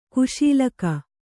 ♪ kuśilaka